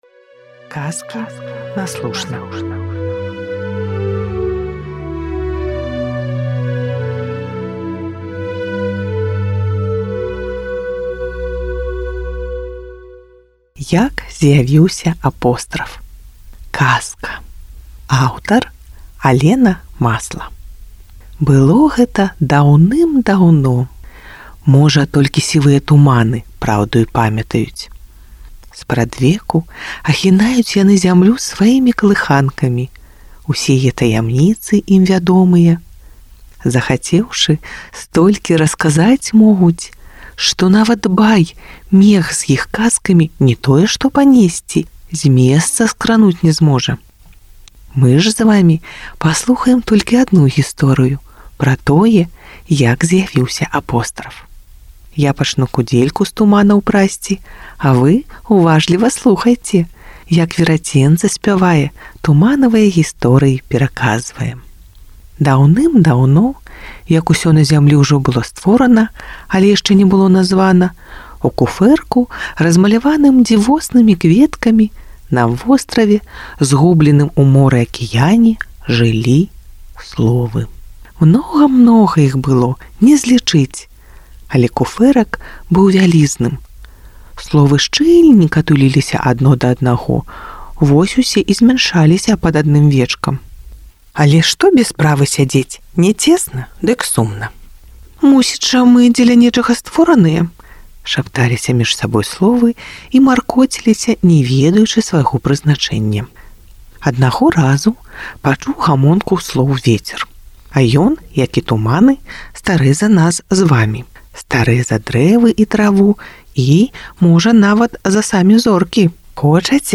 аўдыёказкі142